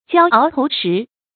焦熬投石 jiāo áo tóu shí
焦熬投石发音